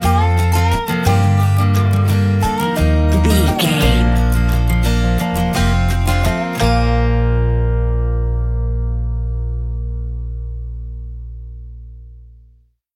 Ionian/Major
B♭
acoustic guitar
bass guitar
banjo
Pop Country
country rock
bluegrass
happy
uplifting
driving
high energy